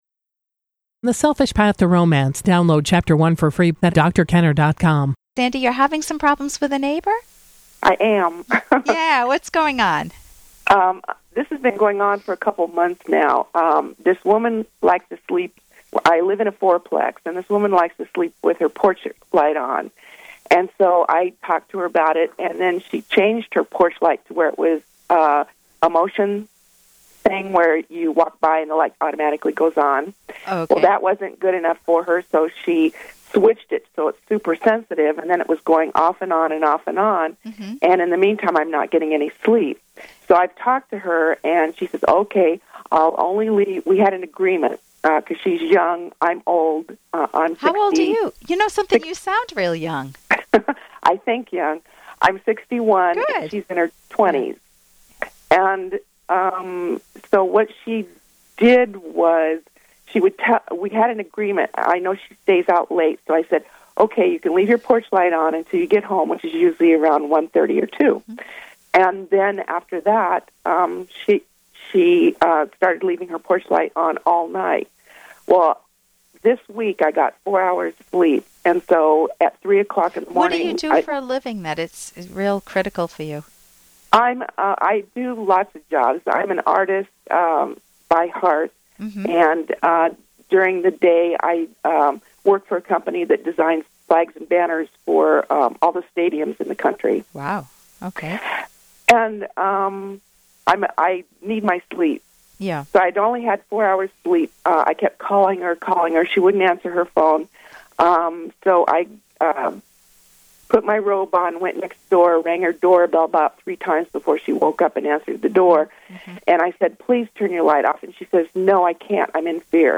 Listen to caller's personal dramas four times each week